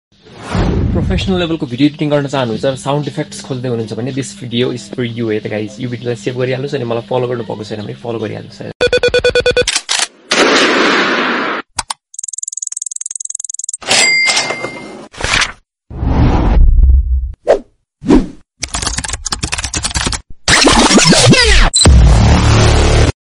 Professional Sound Effects for Creators sound effects free download